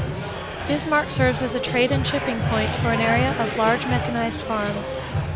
adding the example noisy signal